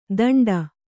♪ daṇḍa